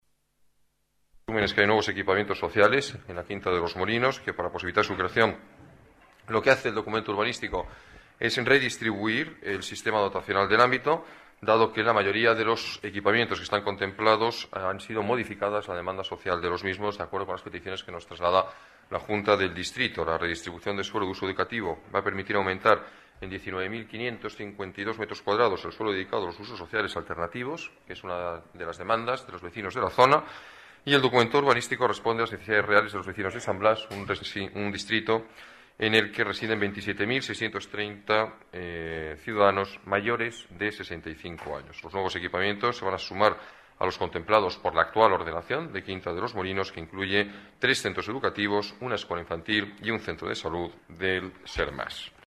Nueva ventana:Declaraciones del alcalde, Alberto Ruiz-Gallardón: Equipamientos sociales en la Quinta de los Molinos